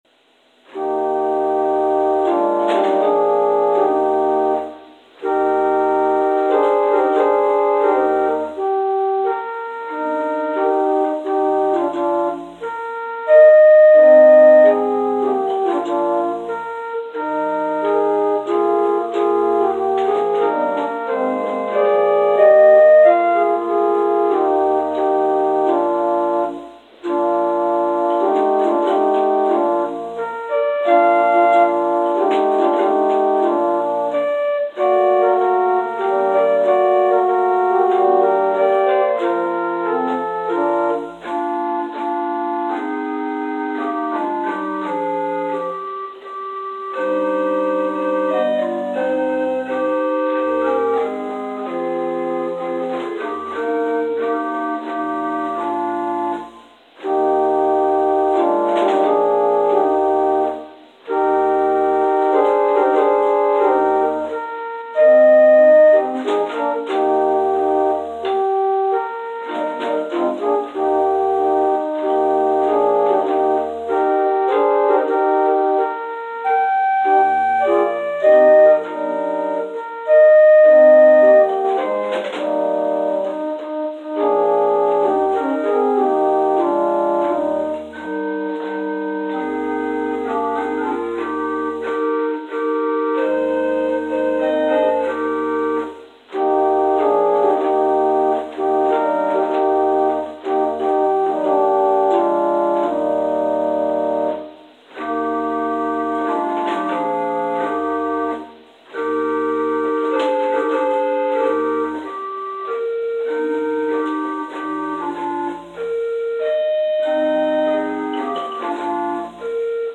Prelude: “God is a Spirit” – William Sterndale Bennett